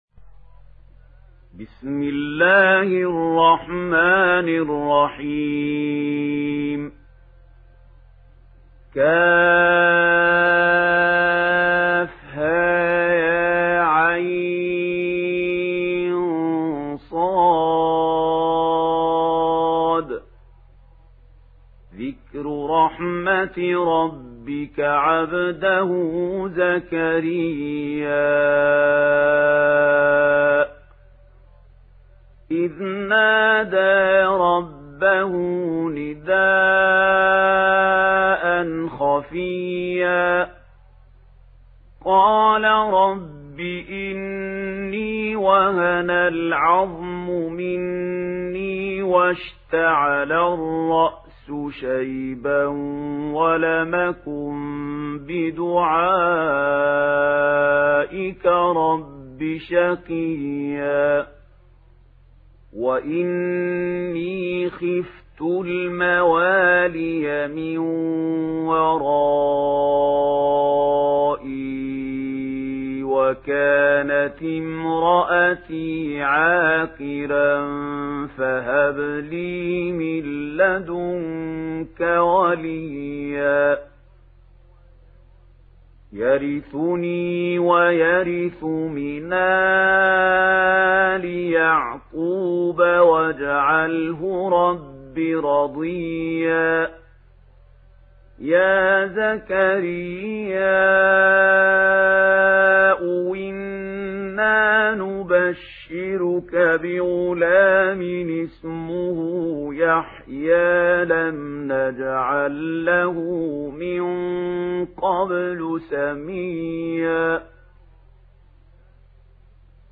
دانلود سوره مريم mp3 محمود خليل الحصري روایت ورش از نافع, قرآن را دانلود کنید و گوش کن mp3 ، لینک مستقیم کامل